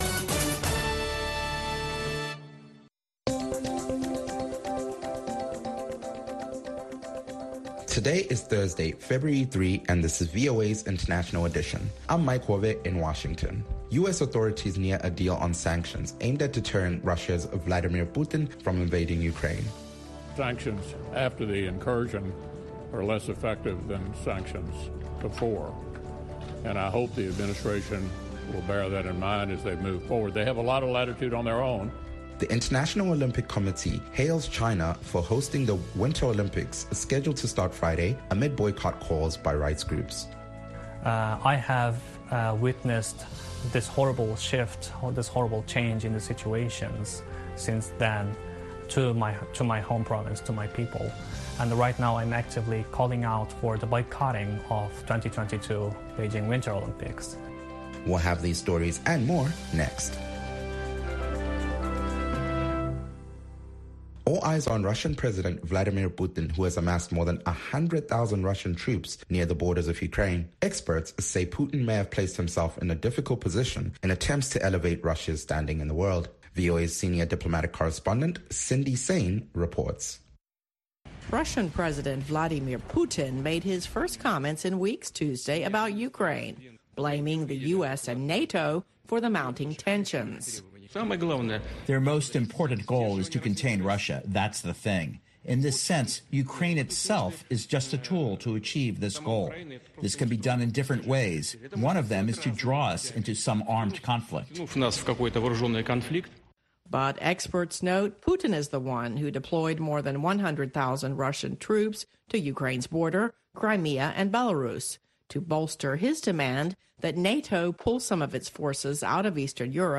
International Edition delivers insight into world news through eye-witnesses, correspondent reports and analysis from experts and news makers. We also keep you in touch with social media, science and entertainment trends